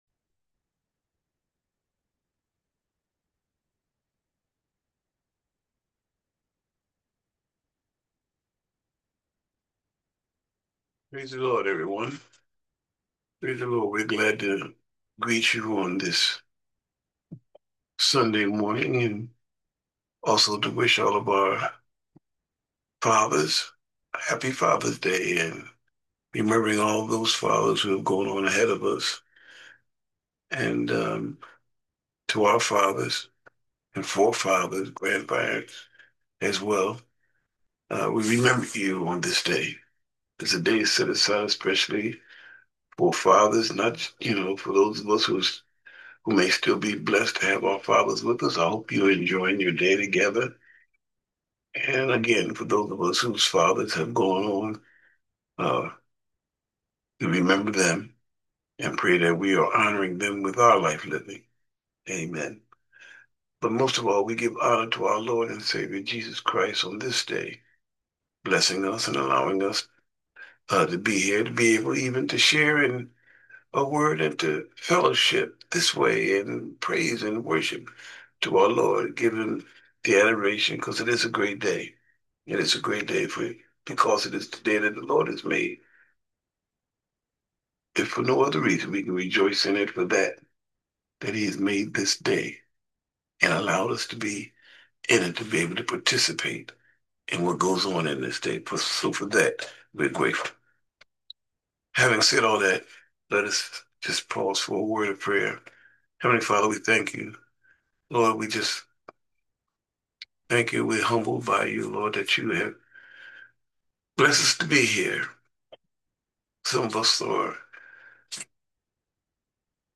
Sermon for Fathers Day 2025: A PROUD POPPA - St James Missionary Baptist Church